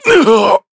Shakmeh-Vox_Damage_kr_03.wav